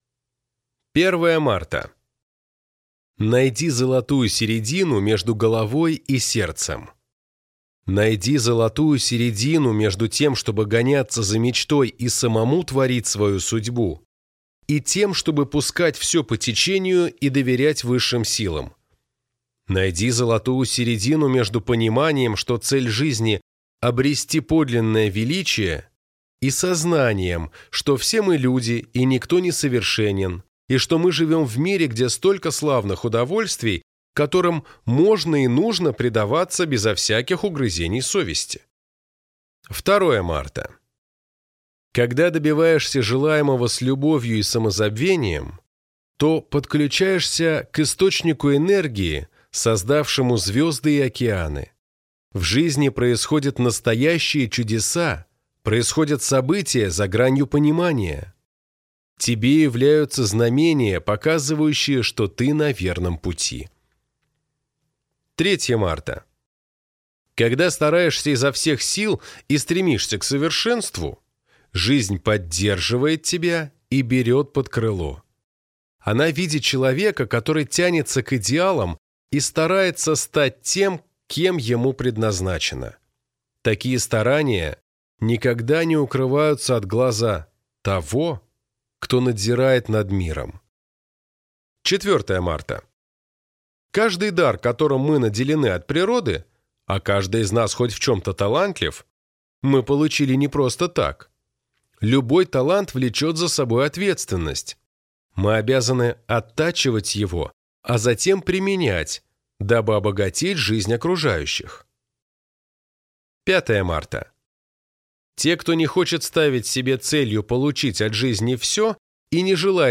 Аудиокнига Книга успеха от монаха, который продал свой «феррари» | Библиотека аудиокниг